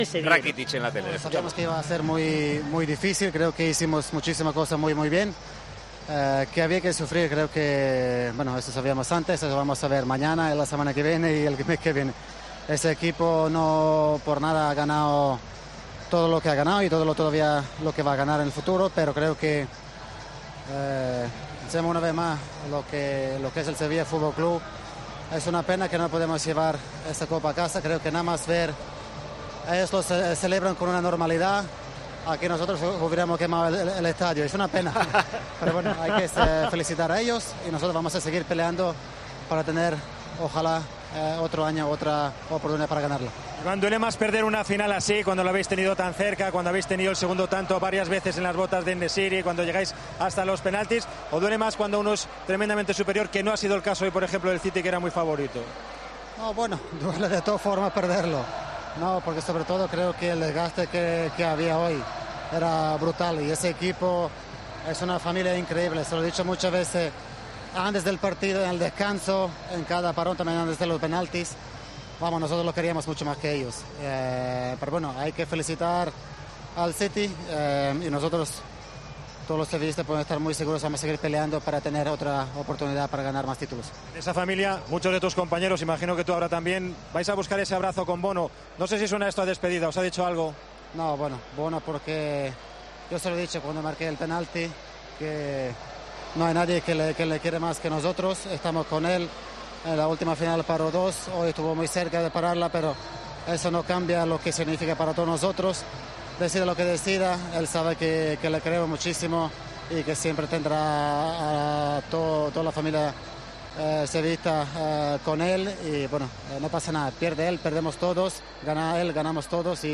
Uno de los capitanes del Sevilla habló en Movistar nada más caer ante el Manchester City en la tanda de penaltis de la final de la Supercopa de Europa.